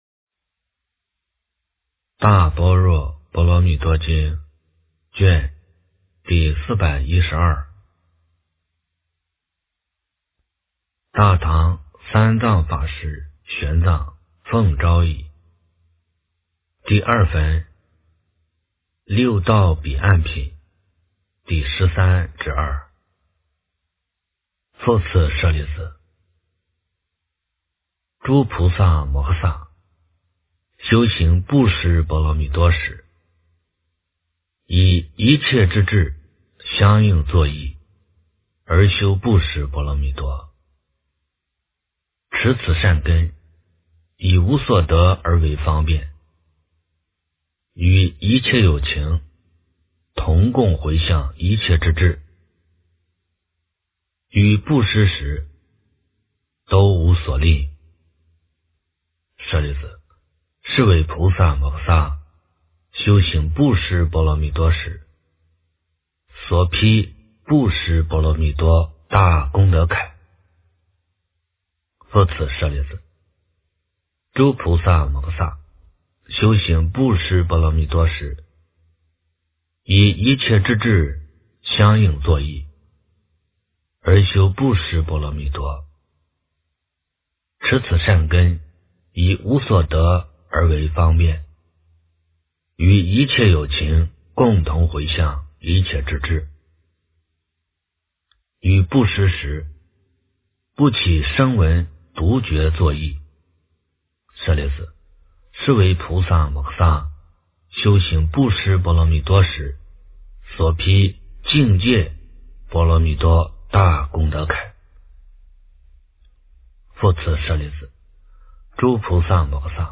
大般若波罗蜜多经第412卷 - 诵经 - 云佛论坛